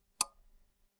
A soft toggle switch sound with a gentle click for on/off state changes
toggle-switch.mp3